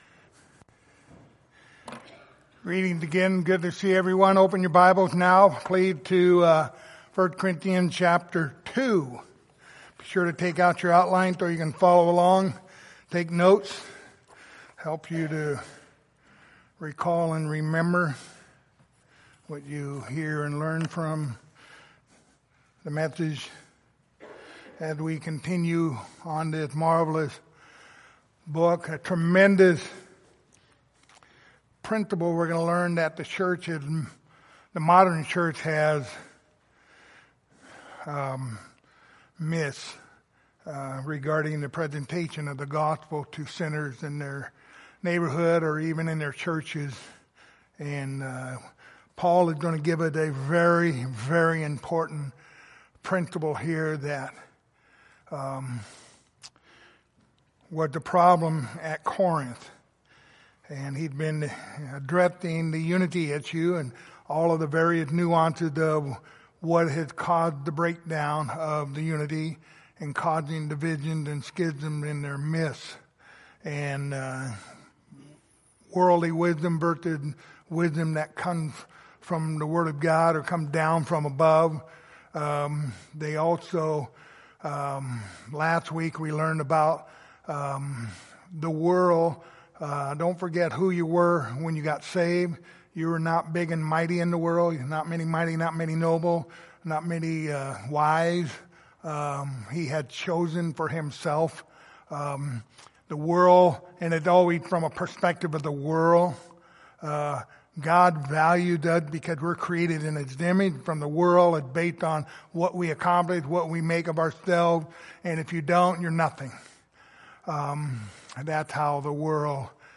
1 Corinthians Passage: 1 Corinthians 2:1-5 Service Type: Sunday Morning Topics